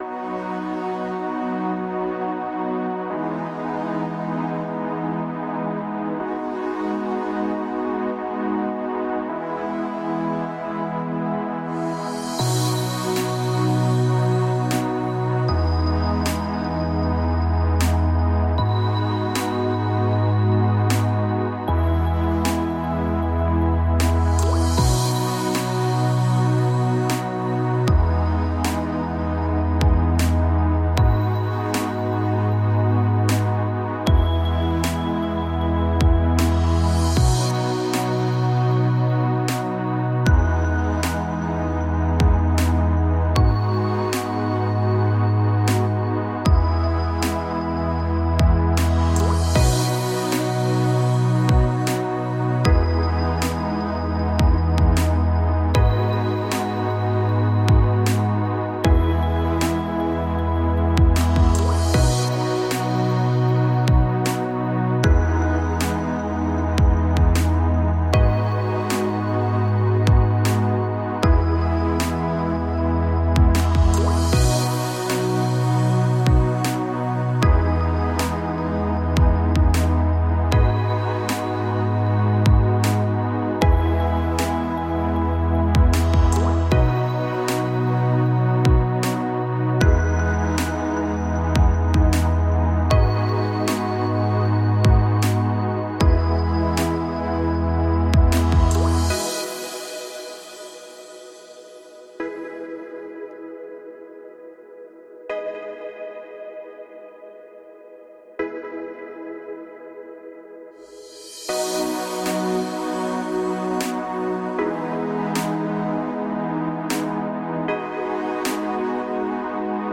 Жизнерадостная музыка для фона в монтаже видео